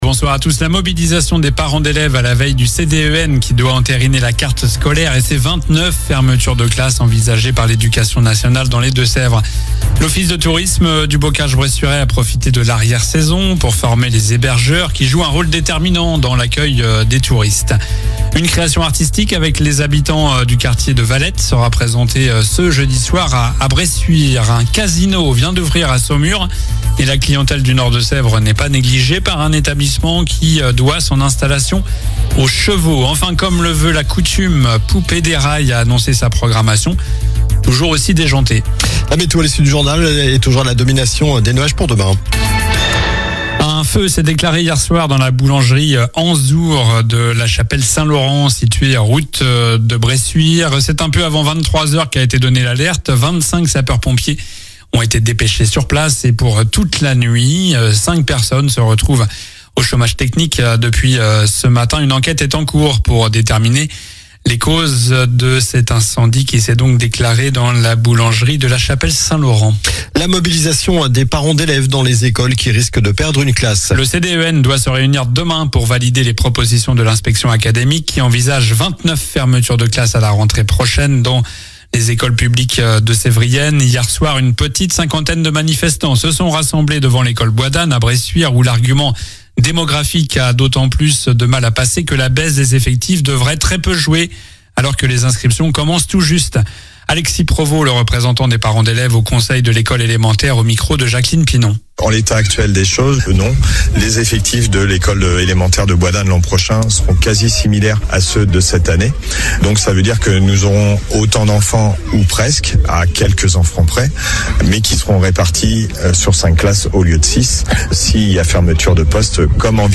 Journal du mercredi 1er avril (soir)